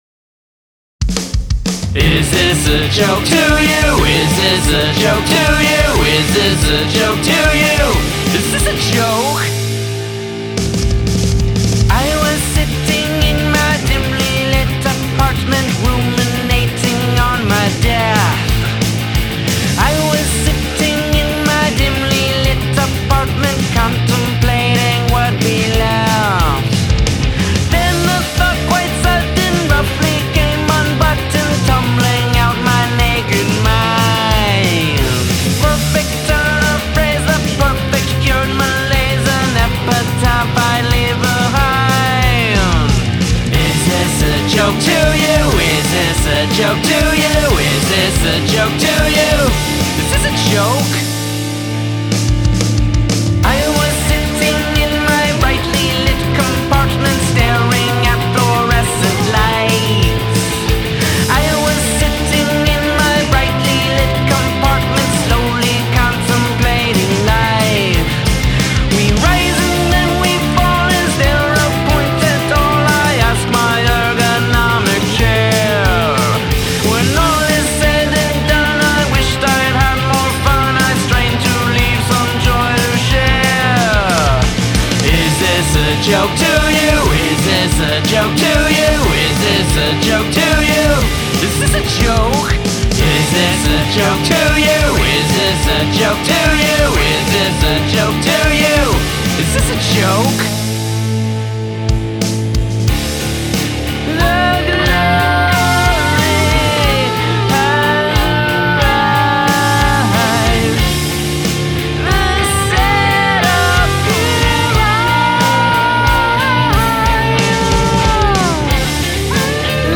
It's a little repetitive but the layering makes up.
The bridge is great.